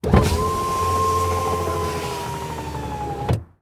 windowdown.ogg